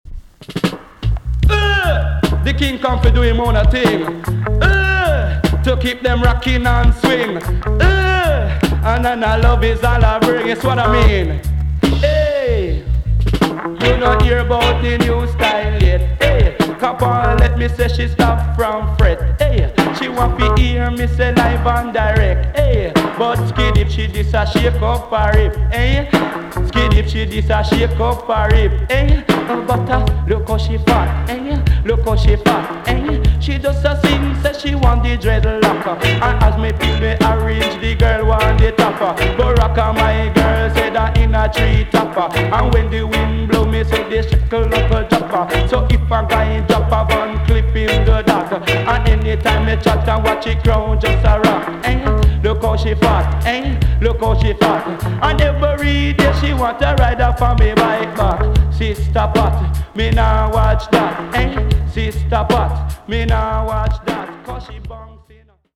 TOP >REGGAE & ROOTS
EX- 音はキレイです。
1981 , NICE DJ TUNE!!